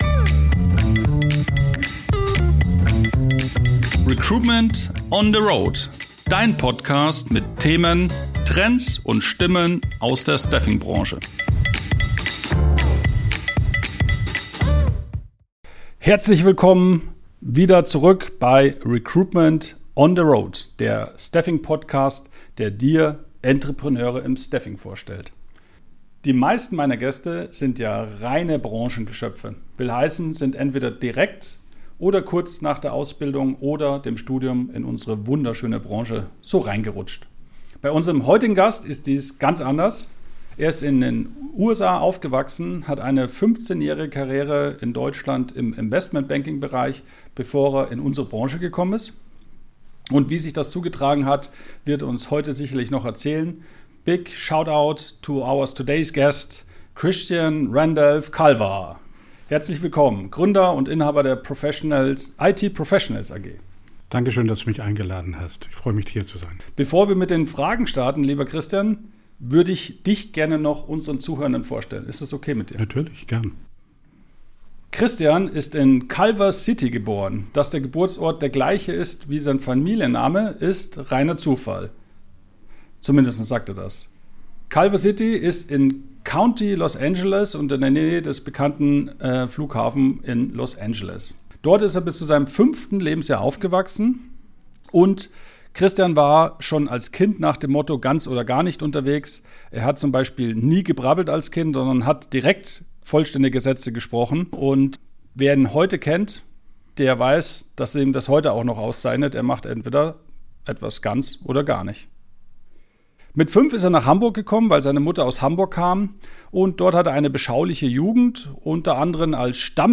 Ein Gespräch über Integrität, internationale Perspektiven und den Mut, es anders zu machen – inklusive Insights zur Diskussion um Scheinselbstständigkeit, Managed Services und „Durchleiter-Geschäft“.